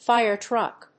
/faɪɚ tɹʌk(米国英語)/
アクセントfíre trùck